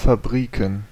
Ääntäminen
Ääntäminen Tuntematon aksentti: IPA: /faˈbʀiːkn̩/ Haettu sana löytyi näillä lähdekielillä: saksa Käännöksiä ei löytynyt valitulle kohdekielelle. Fabriken on sanan Fabrik taipunut muoto.